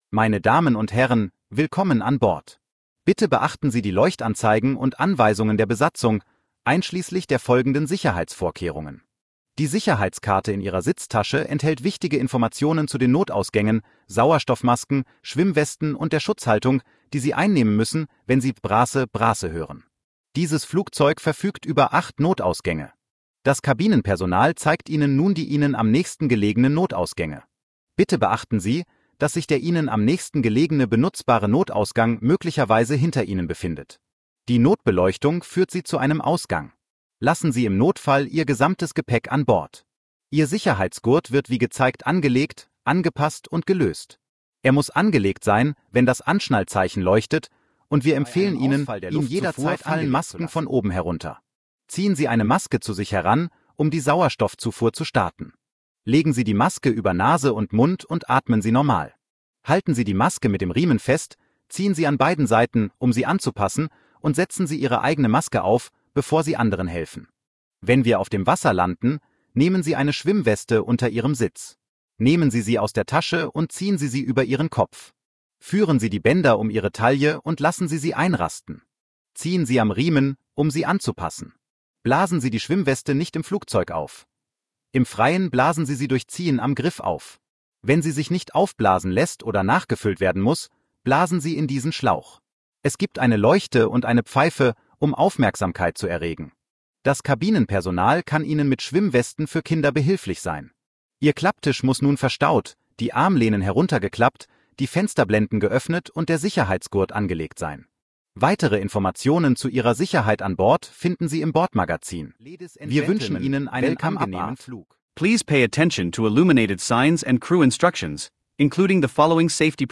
SafetyBriefing.ogg